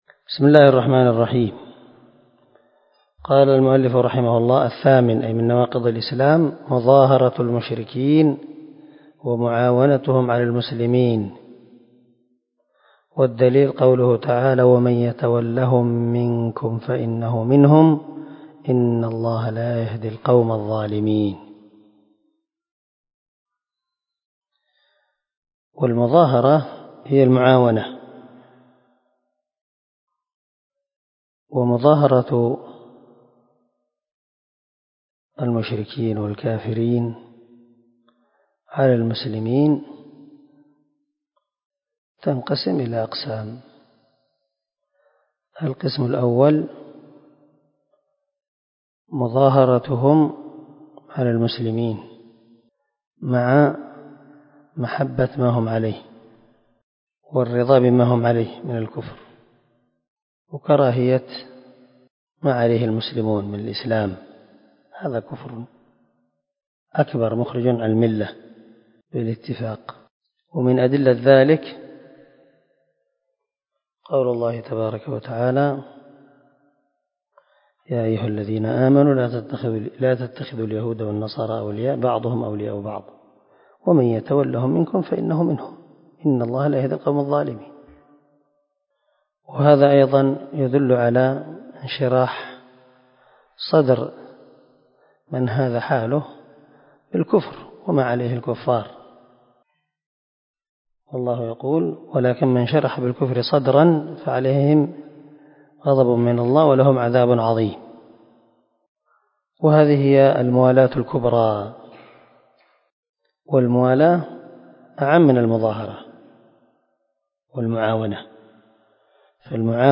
🔊الدرس 18 الناقض الثامن ( من شرح الواجبات المتحتمات)
الدرس-18-الناقض-الثامن.mp3